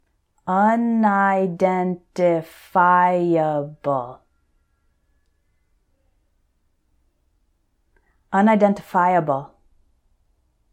So for these words I’ll say them once slowly and once normally, so you can repeat both times.
un – i – den – ti – FI – a – ble………… unidentifiable